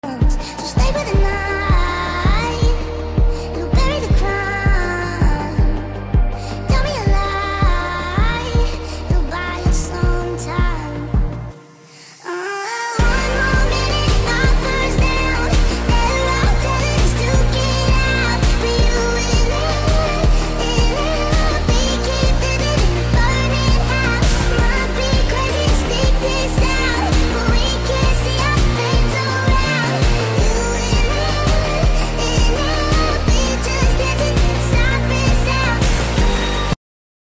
(sped up)